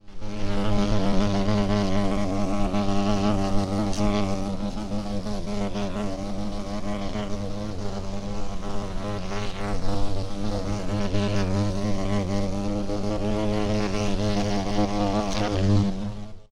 Шмель или другой жук среднего размера летает возле уха
• Категория: Шмель
• Качество: Высокое